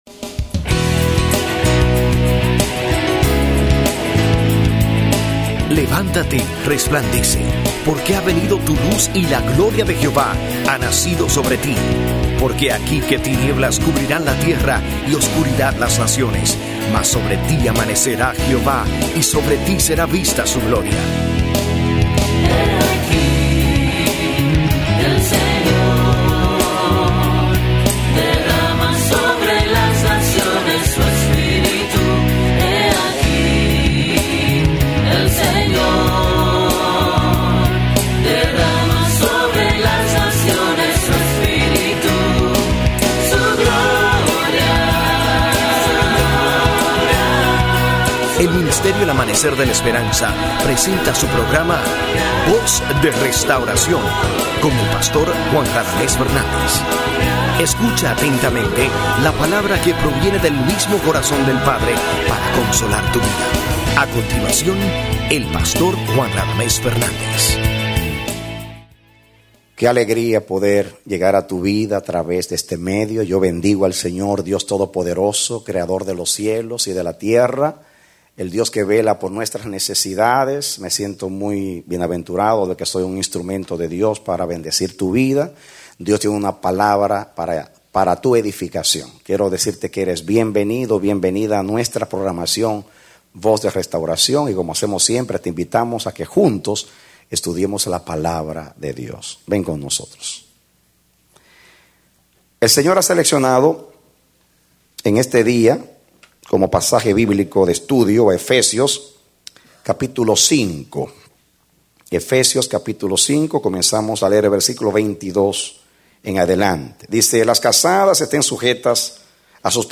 A mensaje from the serie "Mensajes." Predicado Enero 10, 2010